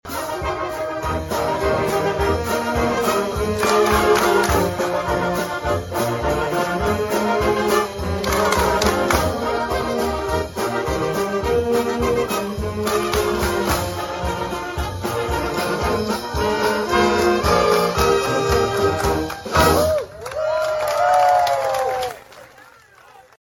Dancing ensued as part of the Emporia Municipal Band’s weekly concert, which followed an active day for the inductees — including a visit with the Emporia State Board of Trustees and a crafts activity at White Auditorium’s Little Theater.
1945-chicken-dance.mp3